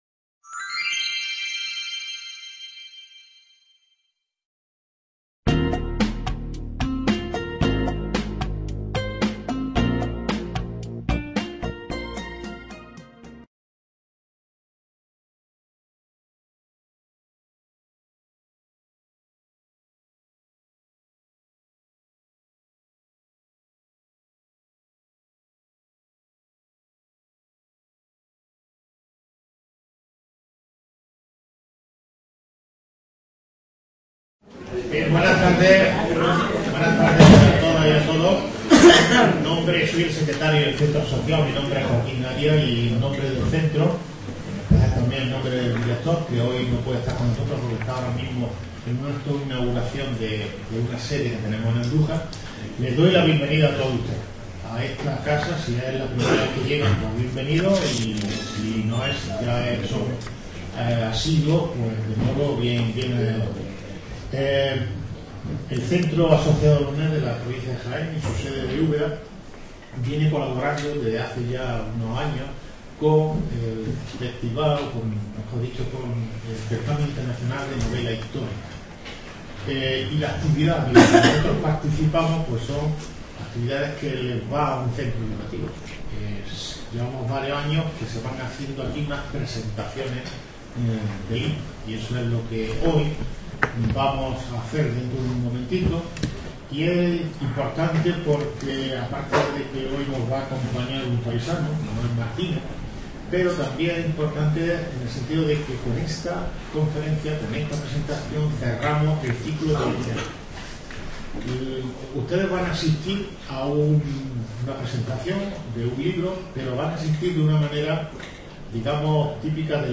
En el contexto del Certamen Internacional de Novela Histórica Ciudad de Úbeda (2018)
un encuentro literario con sus lectores en el Centro Asociado de la UNED en Úbeda